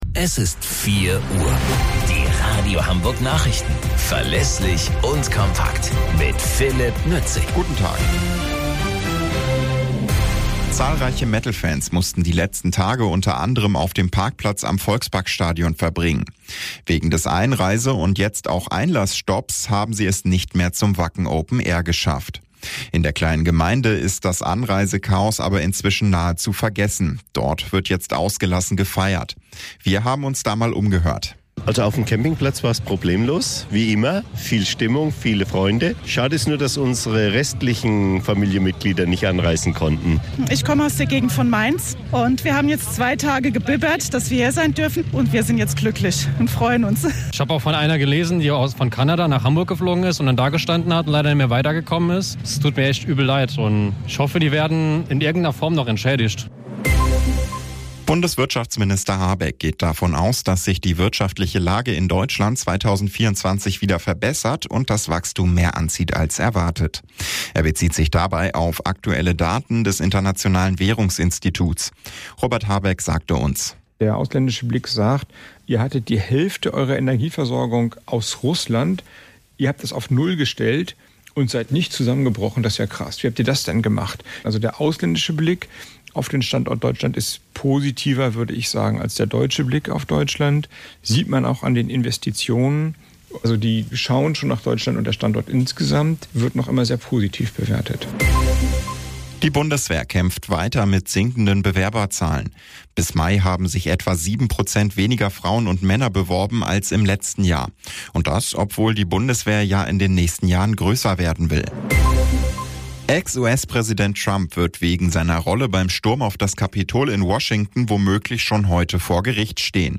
Radio Hamburg Nachrichten vom 04.10.2023 um 21 Uhr - 04.10.2023